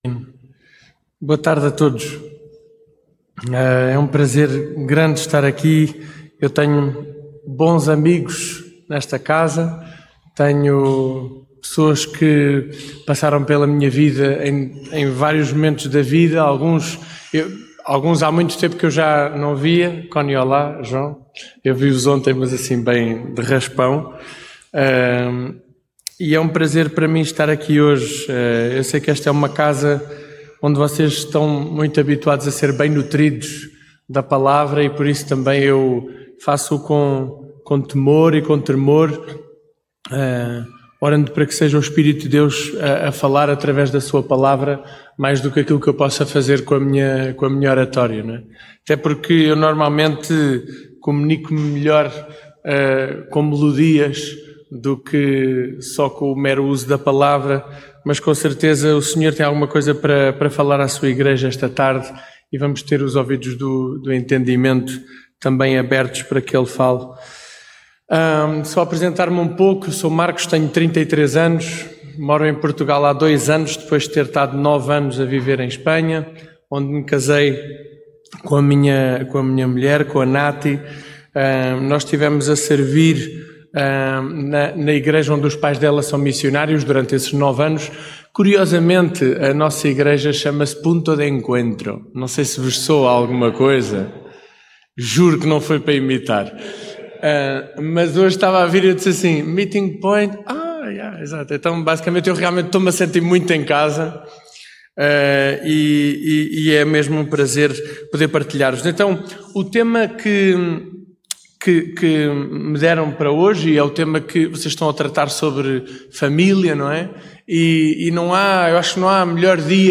retratos familiares mensagem bíblica Filhos desnaturados…